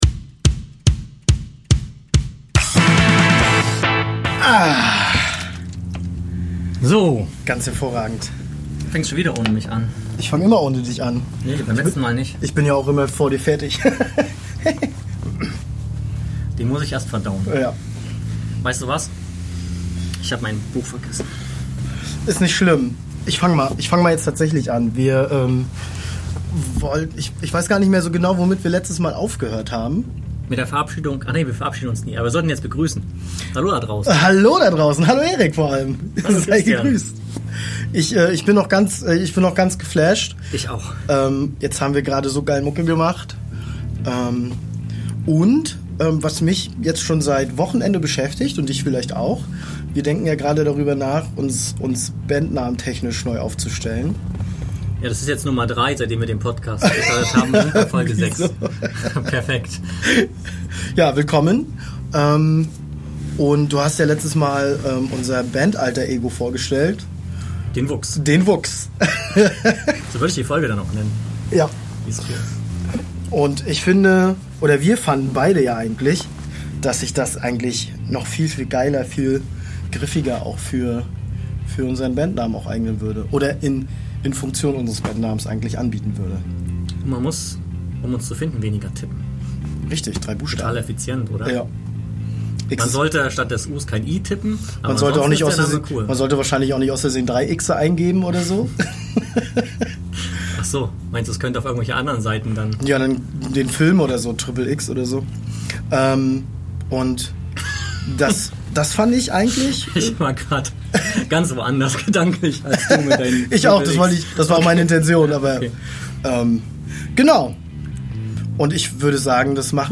Wir verabschieden uns mit einem Jam der neuen Songidee, die auf Social Media gehört werden kann.